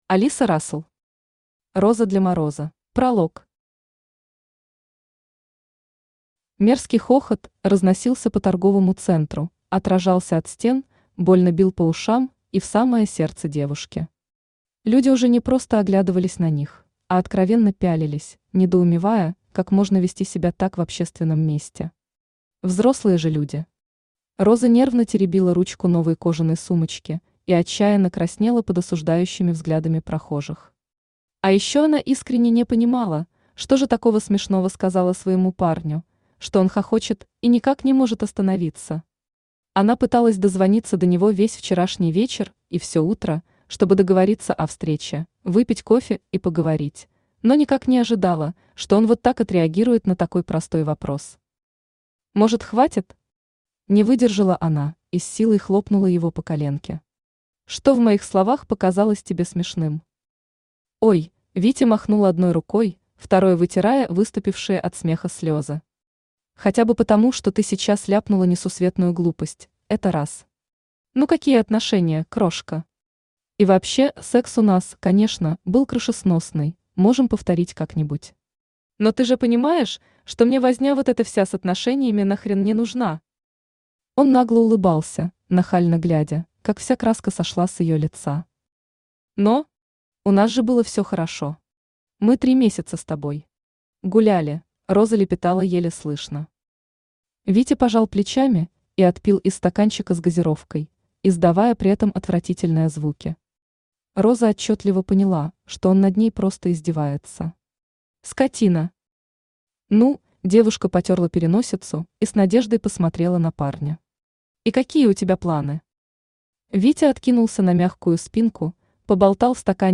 Aудиокнига Роза для Мороза Автор Алиса Рассл Читает аудиокнигу Авточтец ЛитРес.